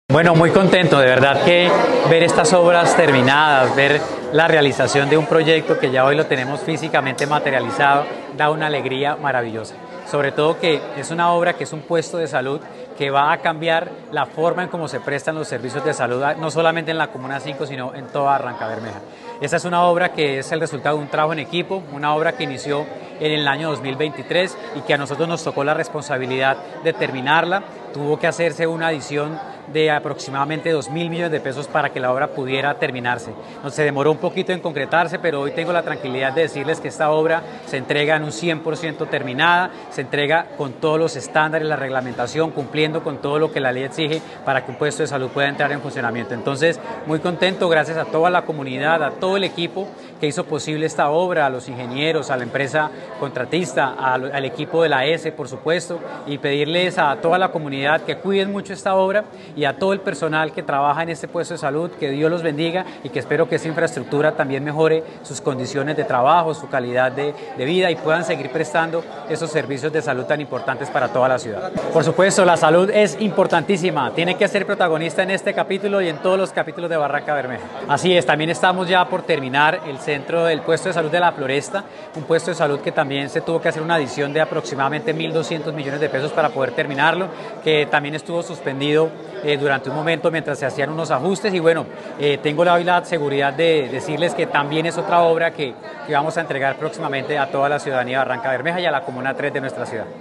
Jonathan Stivel Vásquez Gómez., alcalde de Barrancabermeja